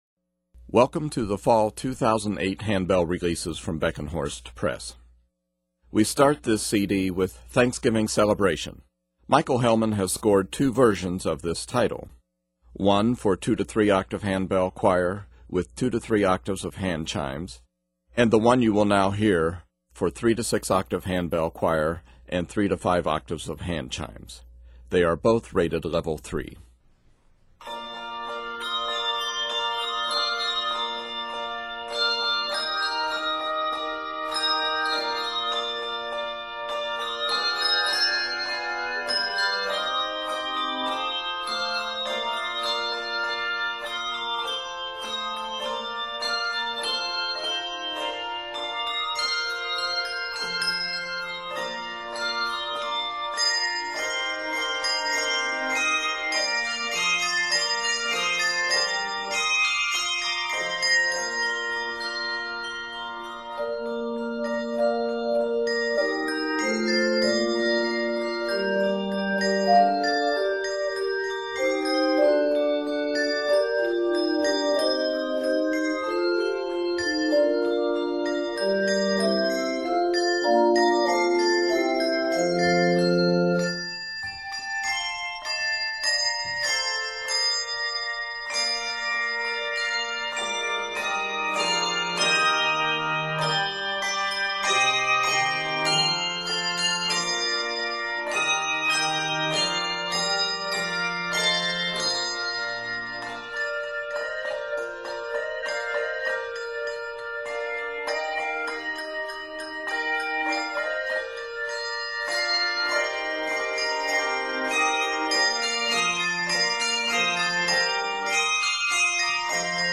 Thanksgiving hymns
Set in Bb Major, this piece is 71 measures.